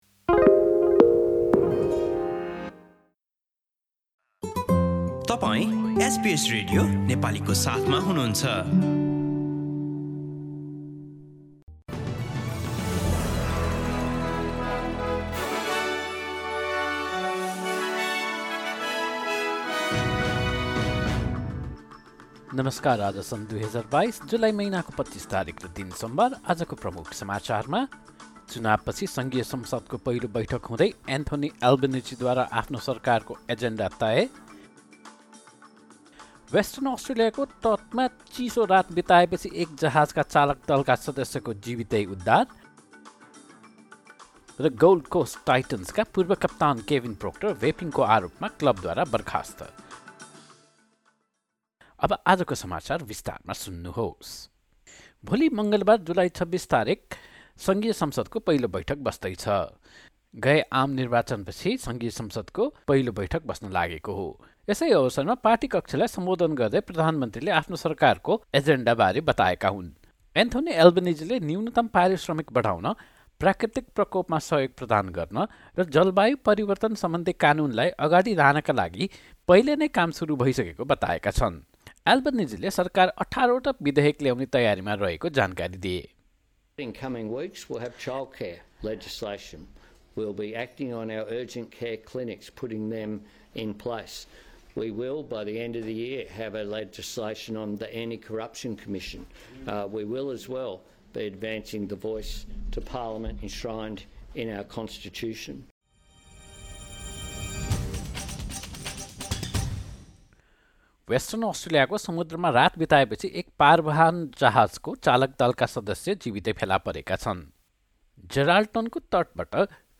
एसबीएस नेपाली अस्ट्रेलिया समाचार: सोमवार २५ जुलाई २०२२